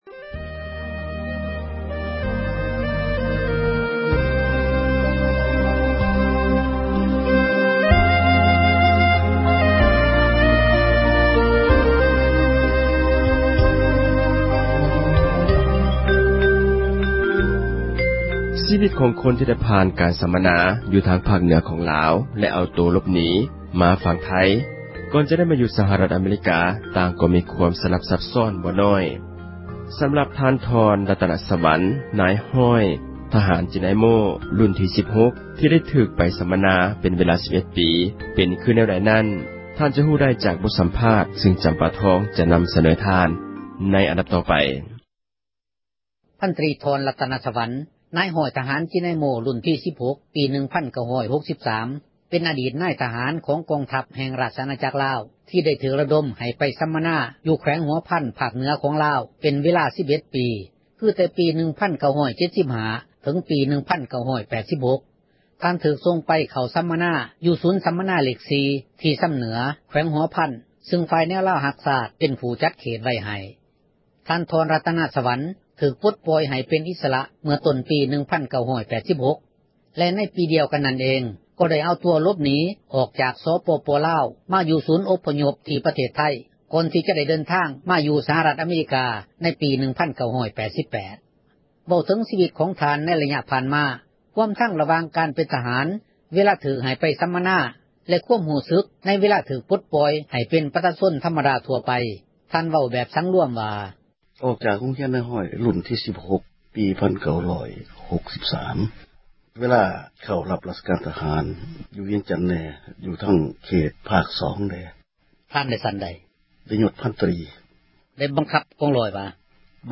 ສໍາພາດອະດີດ ນາຍທະຫານ